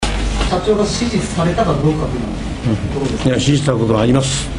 というリバース・スピーチが現れていた。